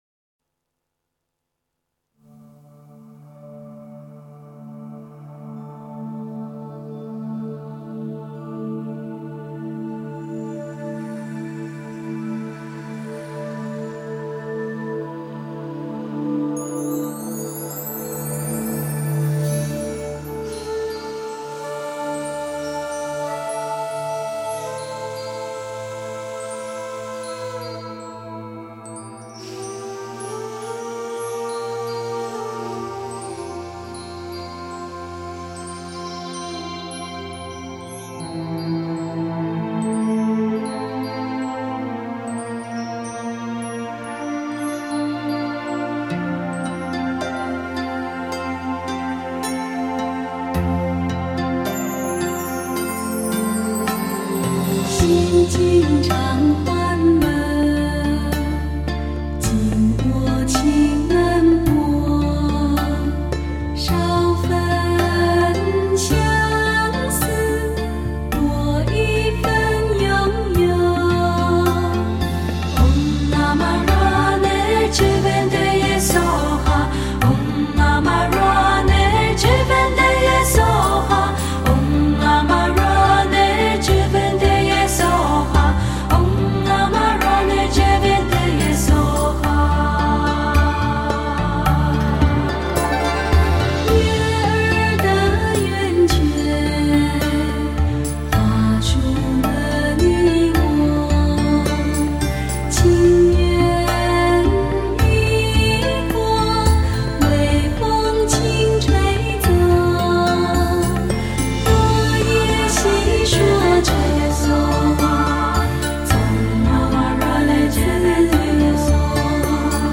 无可挑剔、人声妙灵、制作超凡，远航西藏拍摄最神秘的人间天堂，她代表着一份祝福、一份慈悲、一种和谐……